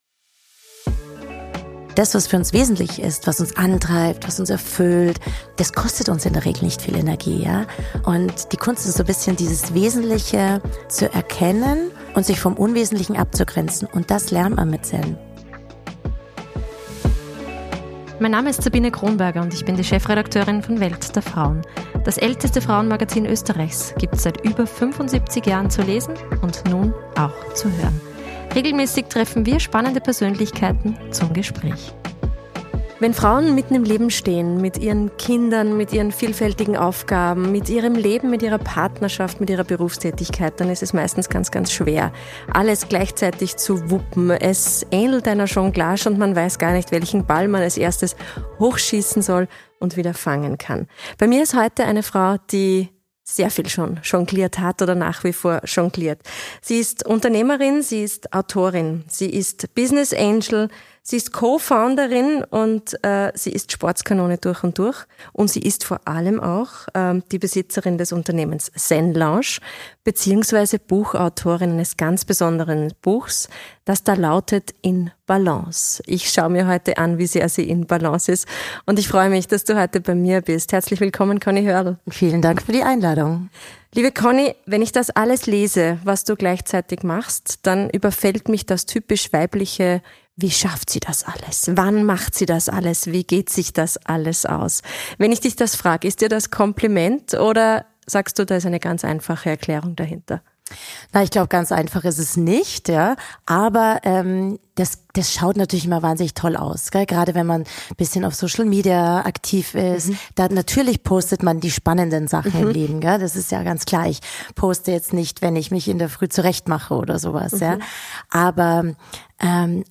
Nur so viel vorab: Perfektion gibt es nicht und wer nach Balance sucht, wird ewig danach streben – ohne anzukommen. Ein Gespräch über Glaubenssätze, Mut, Überwindung und die Erkenntnis der eigenen Kraft.